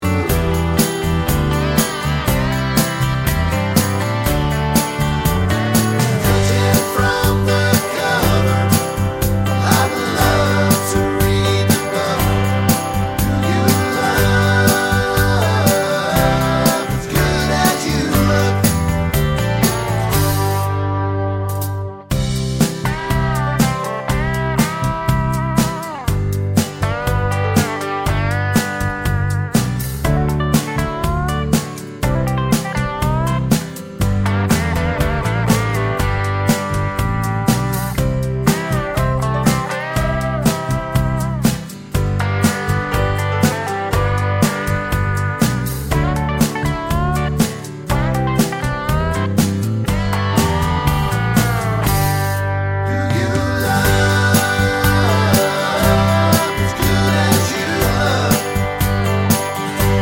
No Harmony Country (Male) 2:58 Buy £1.50